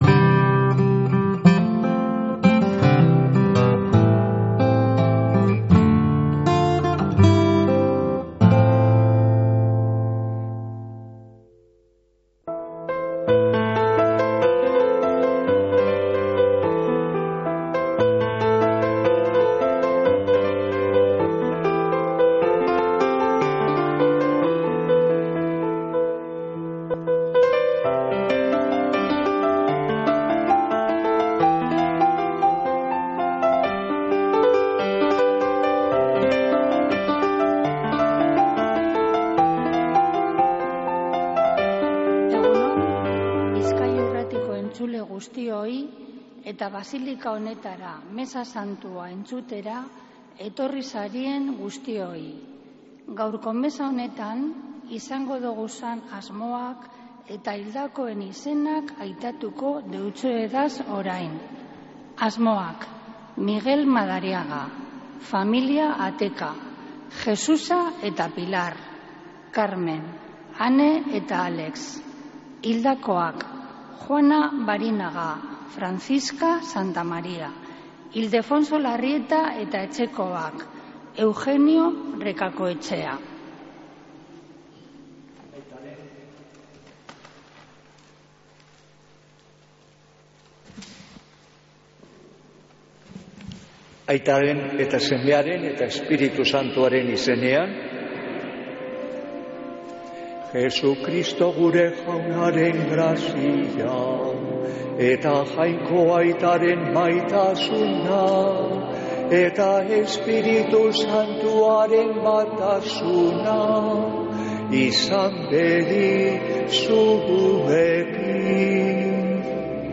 Mezea zuzenean Begoñako basilikatik